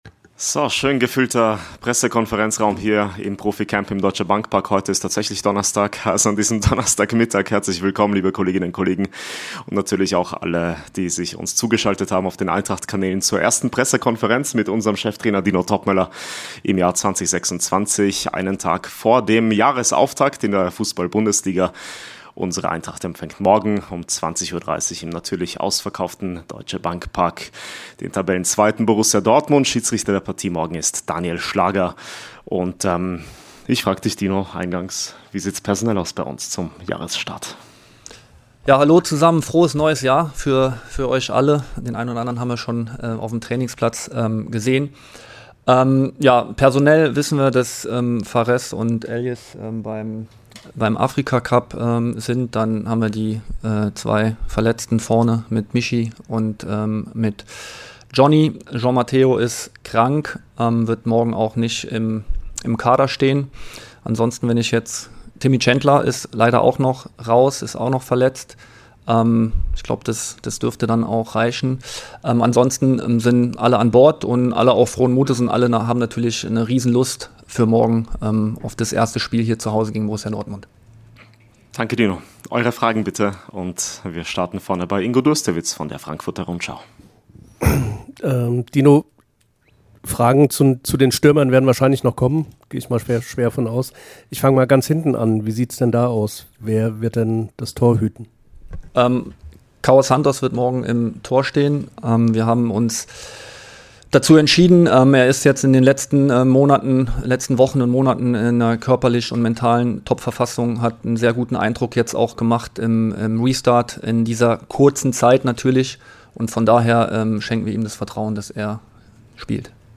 Die Pressekonferenz vor unserem Bundesliga-Heimspiel gegen Borussia Dortmund mit Cheftrainer Dino Toppmöller.